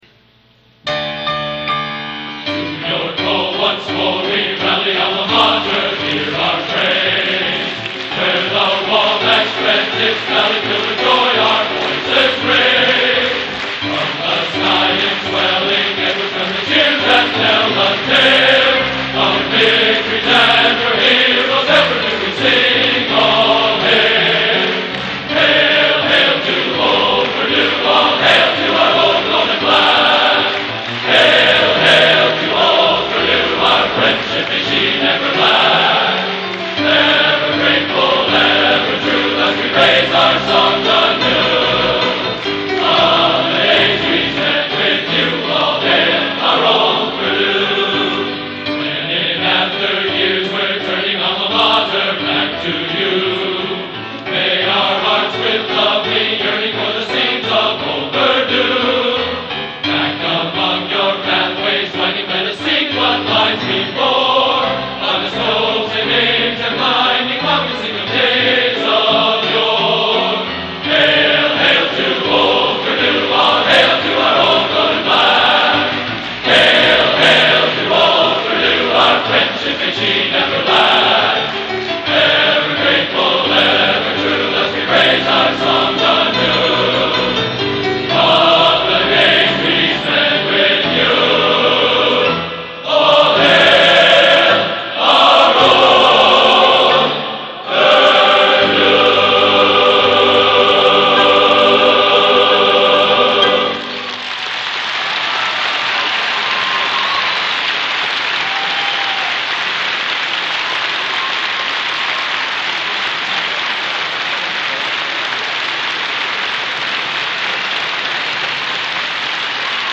Genre: Collegiate | Type: